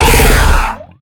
0335ec69c6 Divergent / mods / Soundscape Overhaul / gamedata / sounds / monsters / psysucker / attack_hit_1.ogg 32 KiB (Stored with Git LFS) Raw History
attack_hit_1.ogg